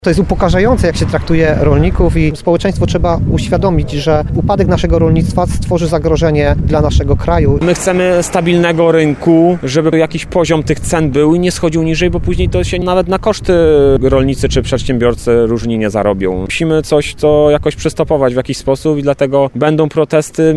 Jak mówili podczas podobnego protestu, zorganizowanego w połowie listopada pod Tarnowem, sprzeciwiają się polityce rolnej rządu.